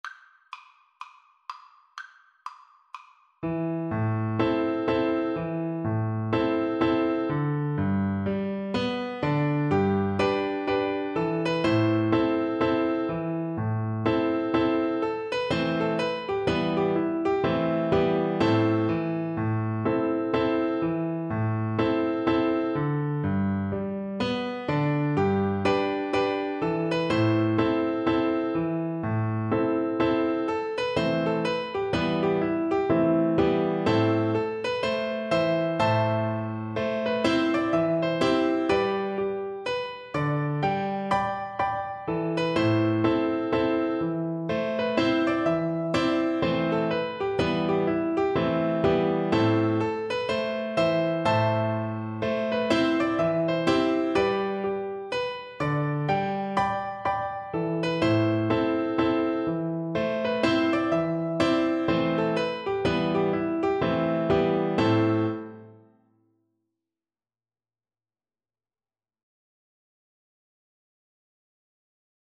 Flute
Traditional Music of unknown author.
G major (Sounding Pitch) (View more G major Music for Flute )
4/4 (View more 4/4 Music)
Presto =c.180 (View more music marked Presto)